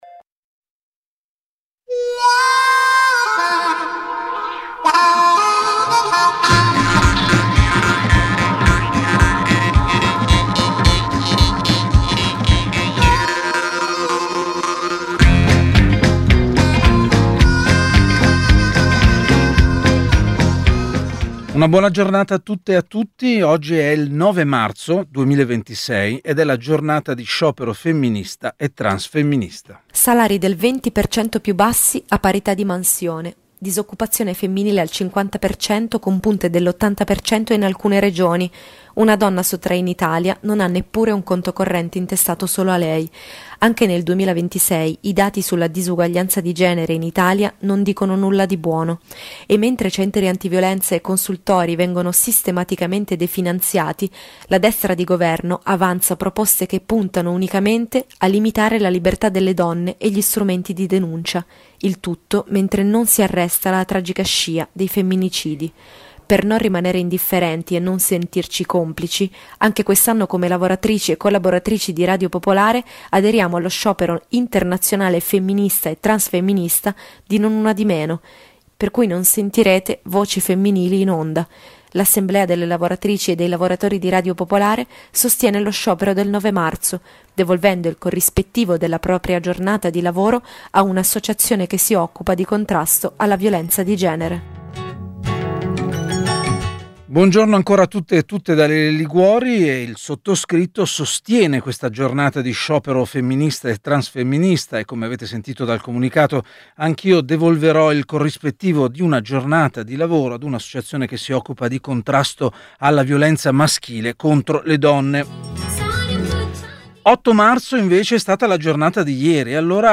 Oggi abbiamo provato ad abbozzare delle risposte riunendo intorno al microfono di Pubblica tre uomini